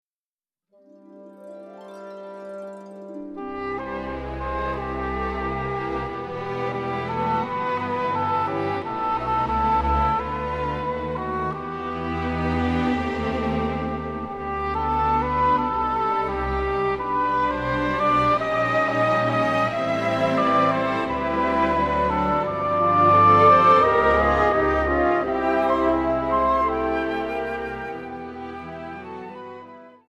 インストゥルメンタル_フルオーケストラ・ヴァージョン